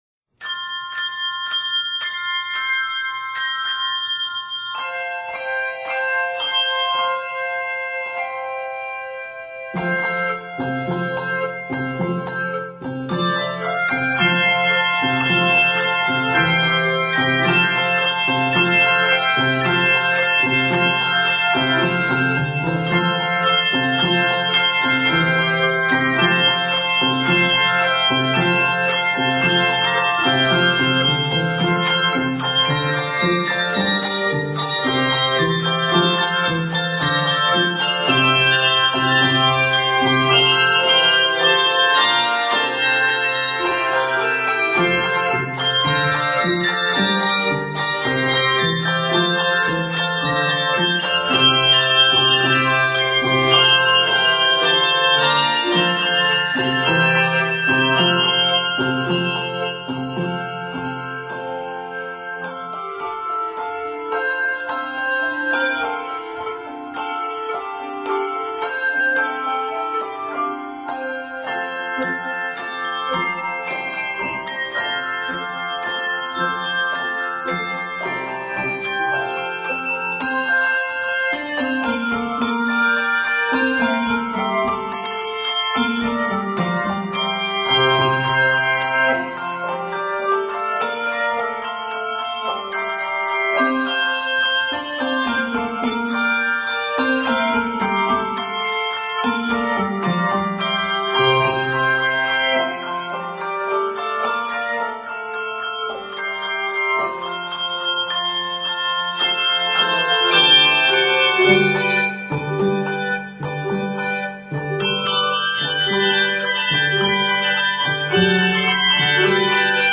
a joyous, happy setting